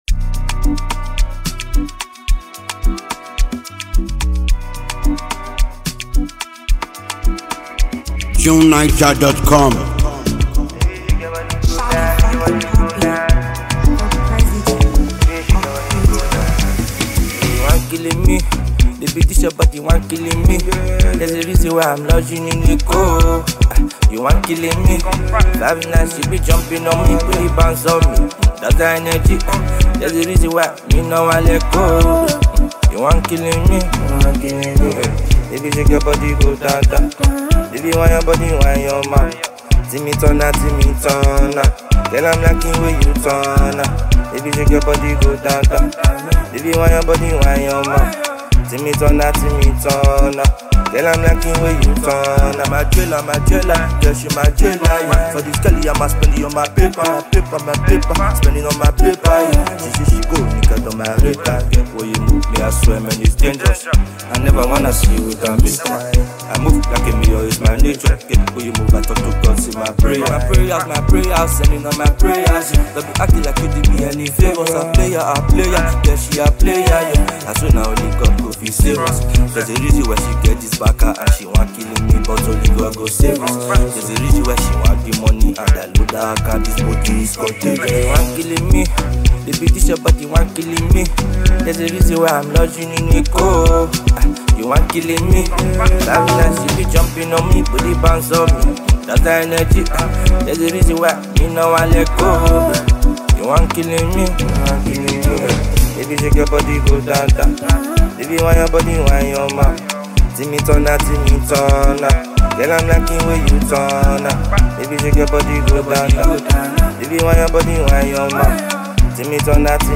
The bright and seasoned vocalist
” another street hymn that will keep you captivated all day.
exciting, street-inspired music